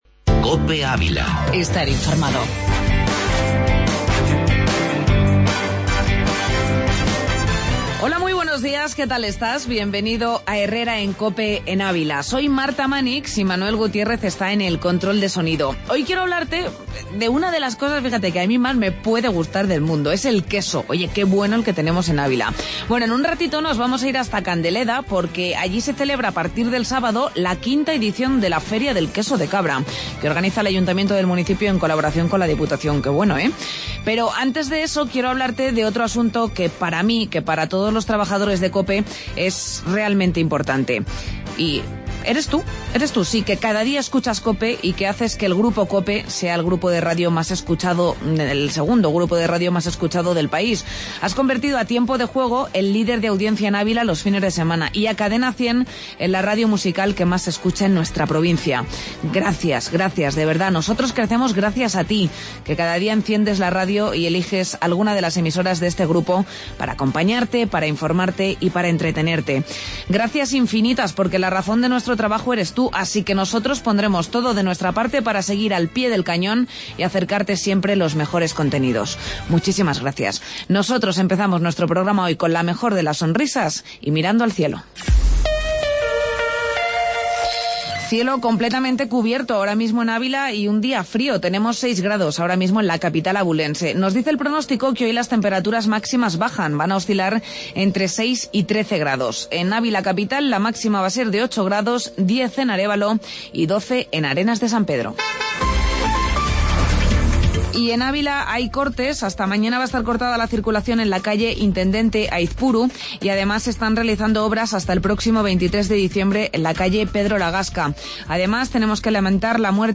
AUDIO: Entrevista Queso de Cabra en Candeleda